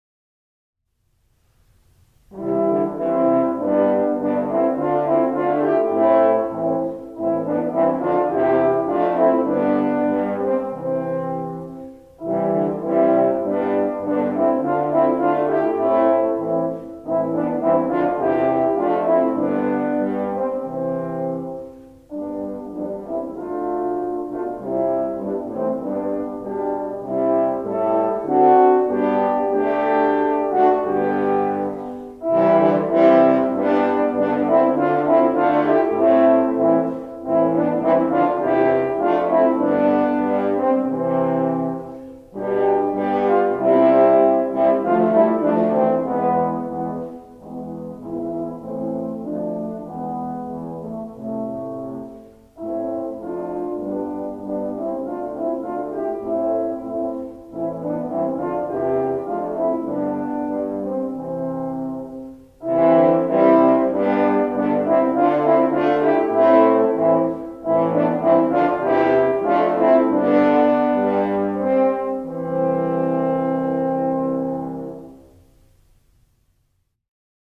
Bearbeitung für Hornquartett
Besetzung: 4 Hörner
arrangement for horn quartet
Instrumentation: 4 horns